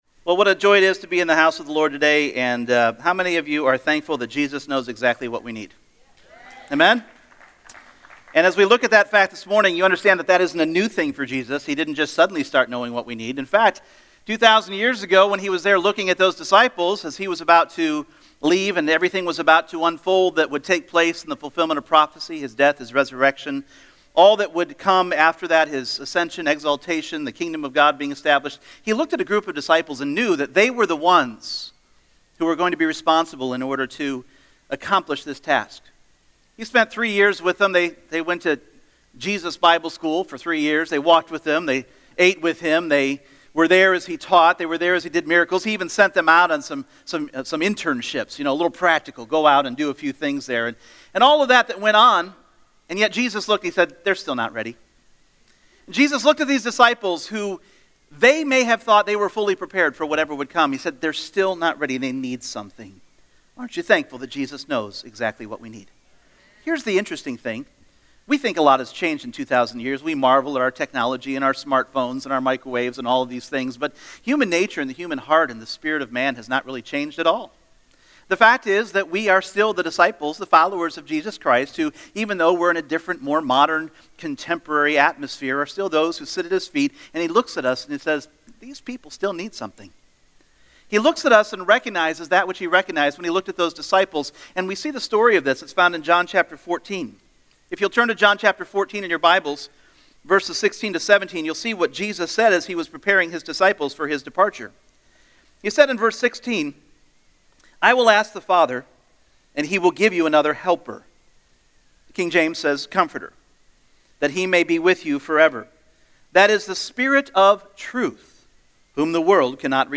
Holy Spirit Service Type: Sunday Morning The Holy Spirit was promised to us by Jesus Himself!